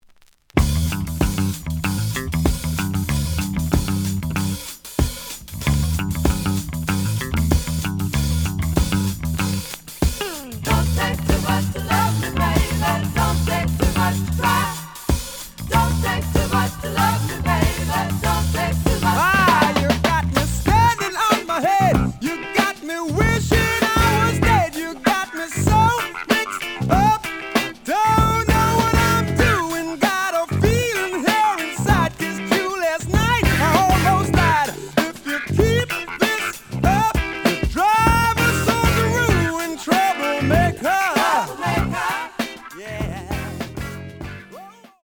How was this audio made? The audio sample is recorded from the actual item.